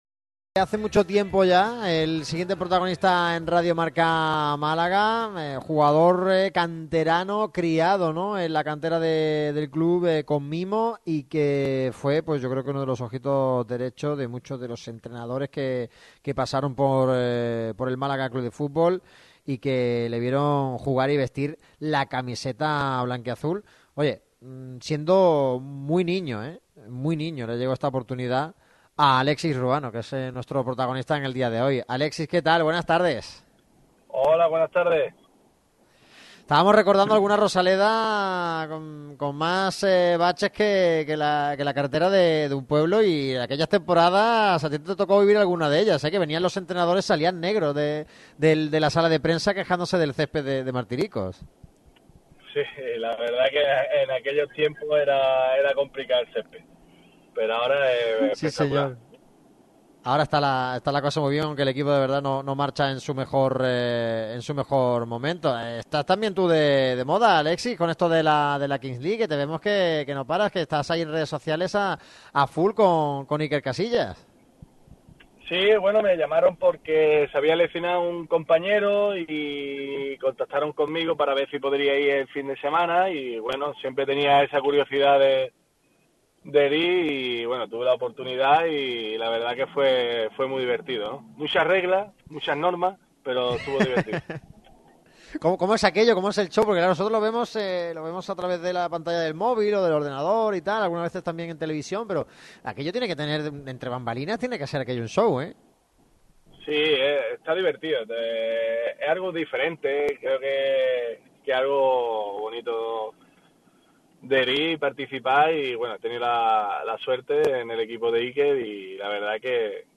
El ex jugador con amplia experiencia en el fútbol español ha hablado con Radio MARCA Málaga en Sertasa.